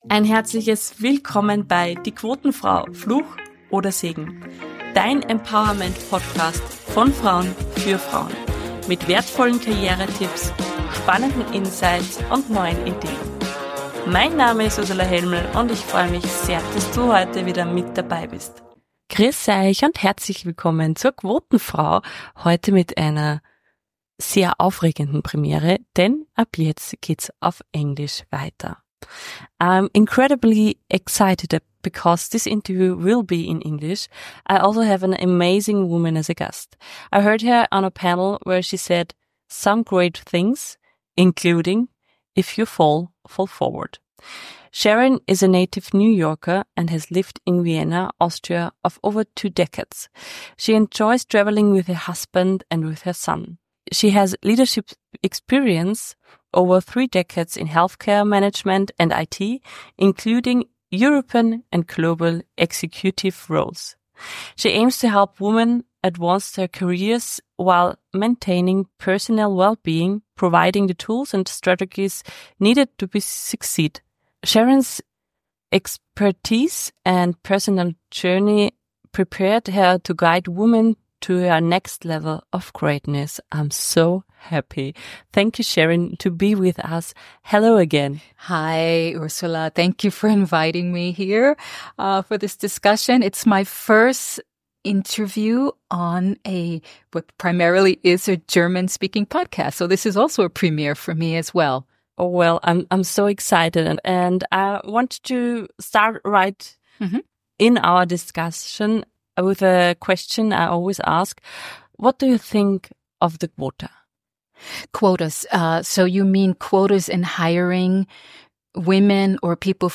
In this fantastic interview, we also talk about goals, boundaries, and what it means to move to a foreign country.